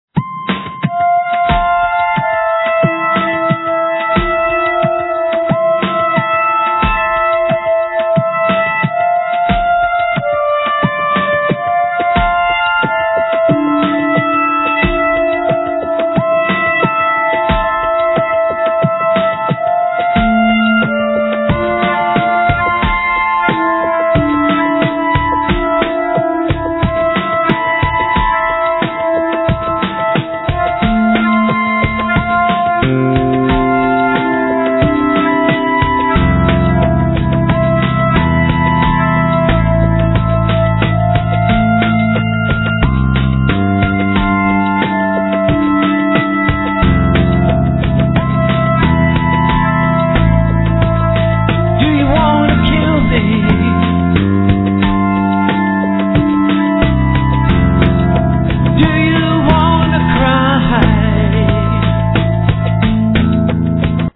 Lead vocals, Ocarina
Guitar, Vocals
Bass, Percussions, Didgeridoo
Saxophone
Flute